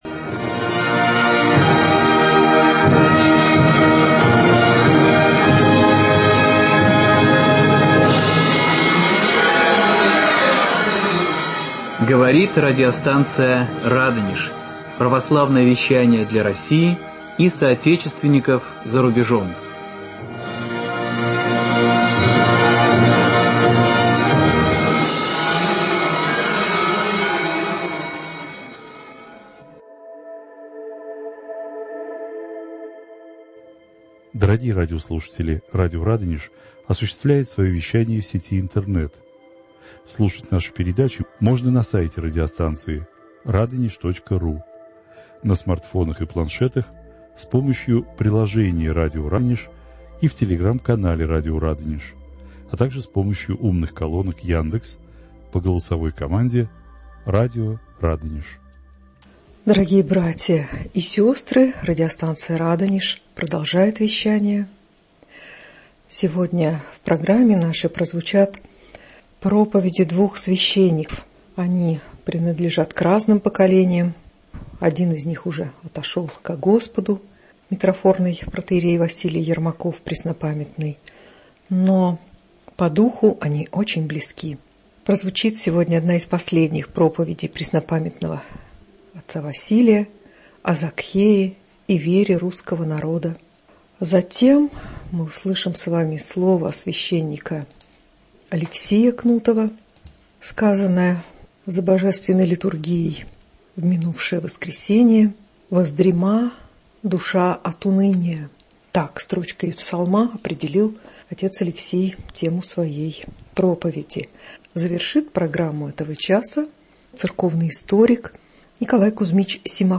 Эта проповедь была произнесена 21 января 2007 года, а 3 февраля батюшки не стало…